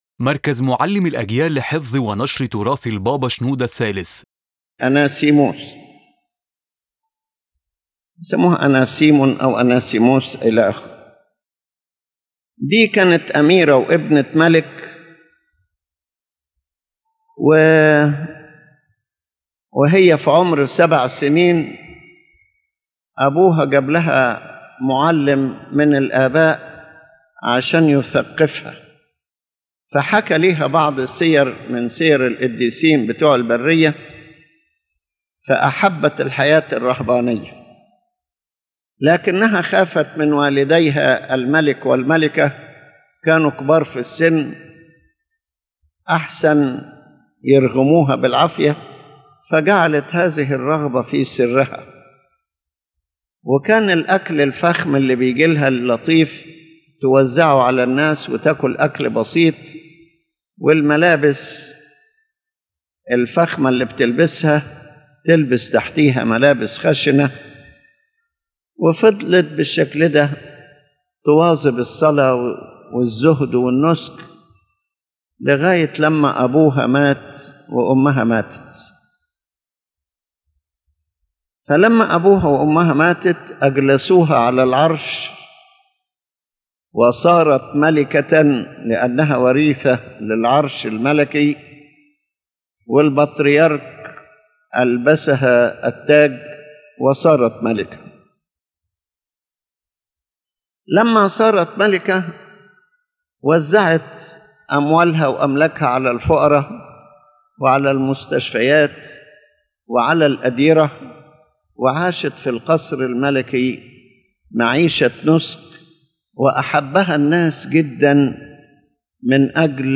The lecture tells the life of a saint who was a princess who inherited the kingdom and became a queen, yet from her childhood she loved monastic life and asceticism after she learned the lives of the saints.